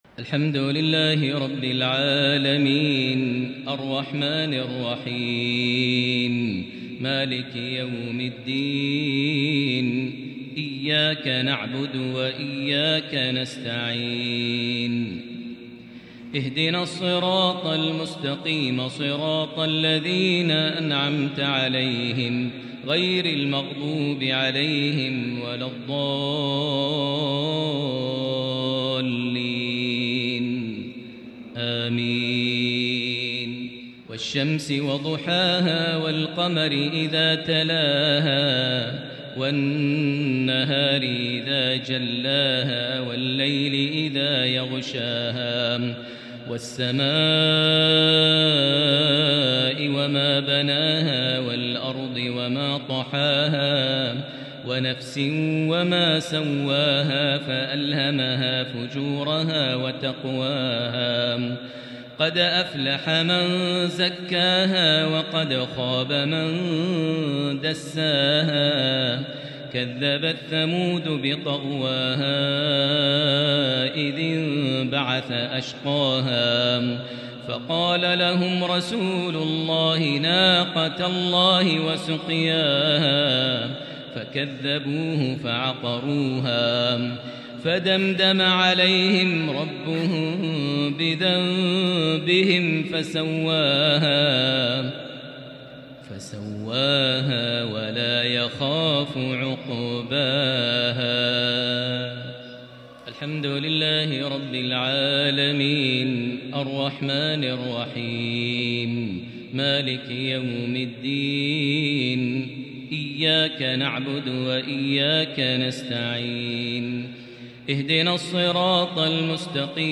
salah_jumua_prayer from 8-7-2022 Surah Ash-Shams + Surah Al-Lail > 1443 H > Prayers - Maher Almuaiqly Recitations